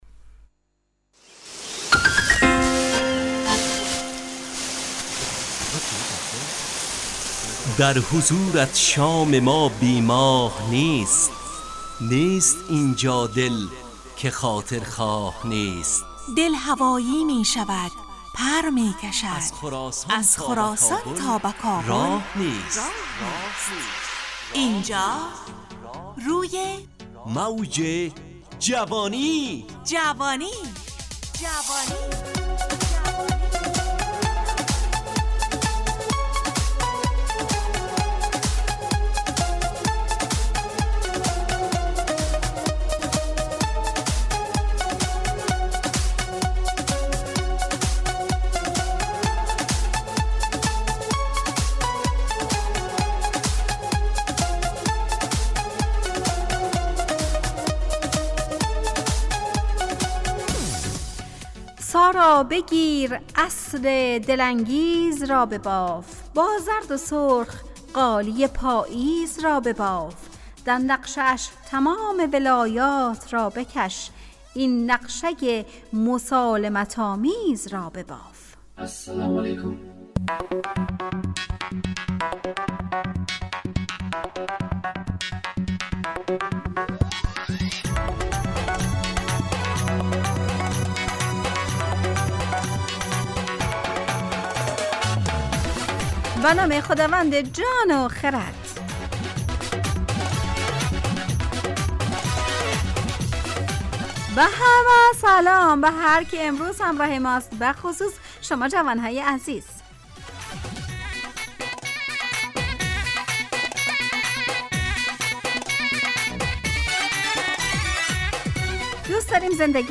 روی موج جوانی، برنامه شادو عصرانه رادیودری.
همراه با ترانه و موسیقی مدت برنامه 55 دقیقه . بحث محوری این هفته (مراقبت ) تهیه کننده